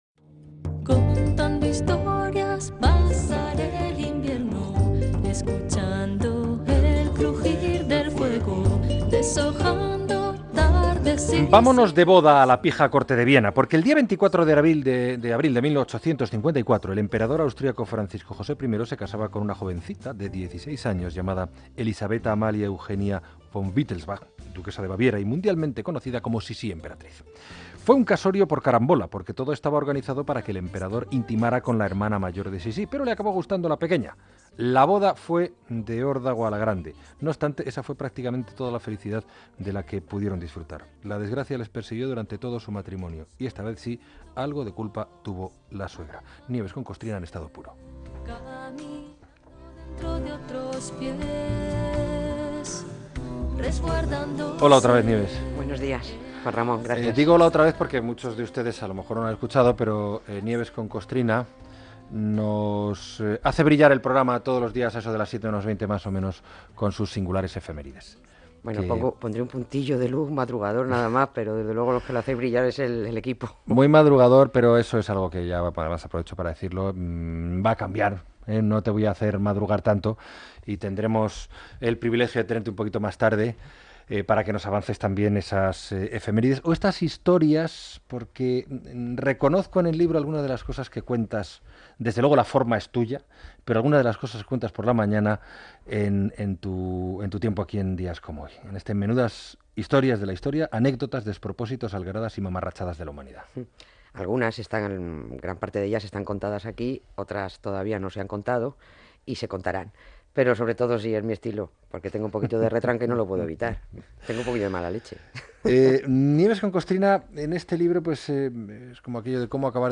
1e0bb1415ed5c8bd91f676ca4a890b6be92e7661.mp3 Títol Radio Nacional de España Emissora Radio Nacional de España Barcelona Cadena RNE Titularitat Pública estatal Nom programa En días como hoy Descripció Anècdota històrica d'Elisabet de Baviera (Sissi l'emperatriu). Entrevista a la col·laboradora del programa Nieves Concostrina que presenta el llibre 'Menudas historias de la historia" i explica algunes curiositats i efemèrides (la Base dels EE.UU a Guantánamo (Cuba), Fuenteovejuna, el"chotis", etc.)
Info-entreteniment